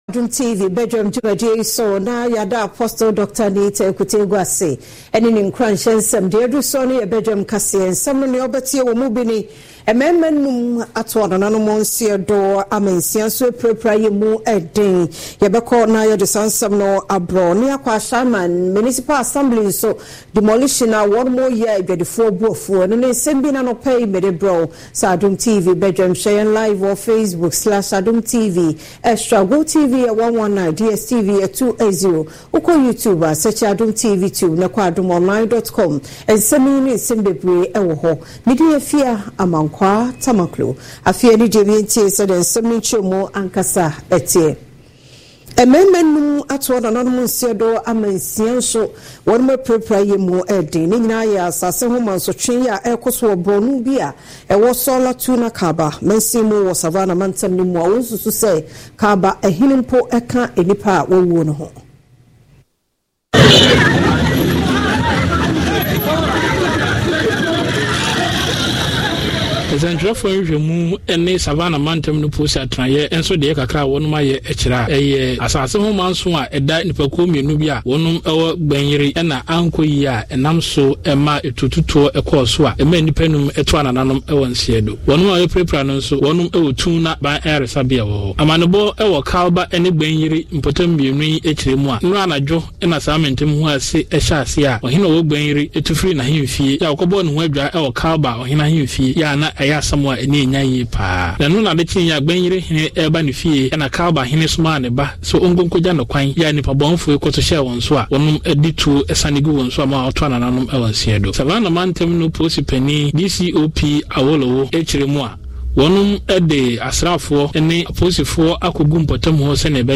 Discussion of the major headlines in the various credible newspapers with resource persons.